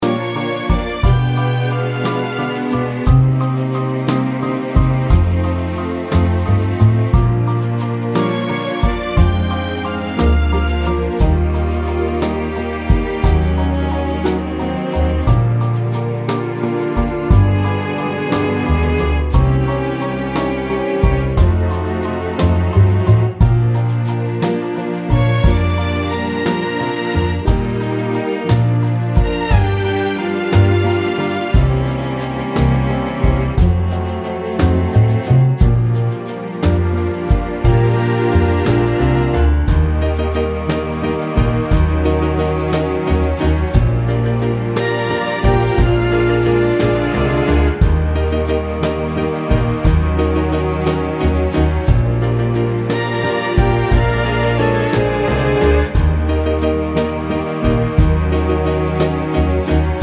For Solo Female Jazz / Swing 3:10 Buy £1.50